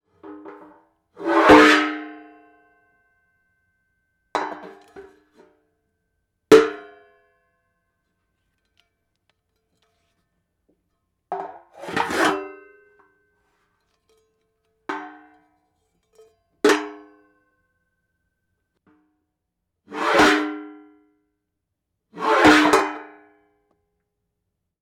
Pots and Pans
ding dishes pans pots restaurant sound effect free sound royalty free Sound Effects